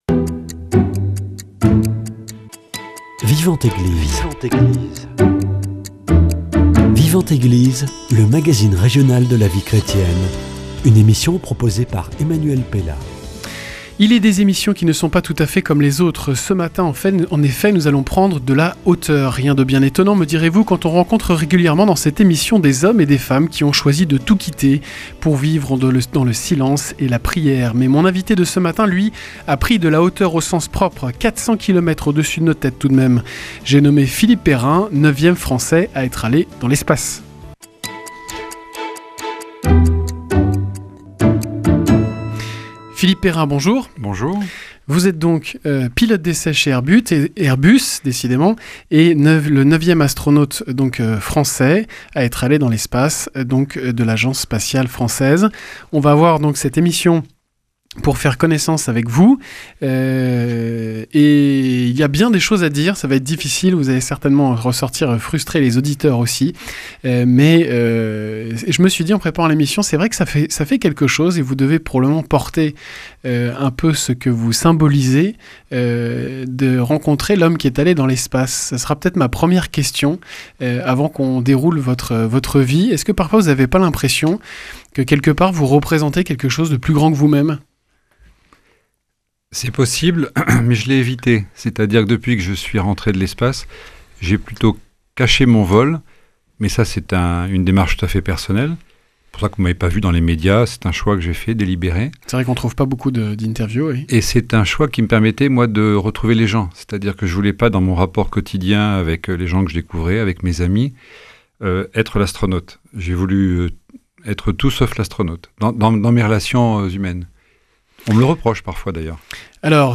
Avec Philippe Perrin, pilote d’essai chez Airbus, 9e astronaute français de l’Agence Spatiale Française.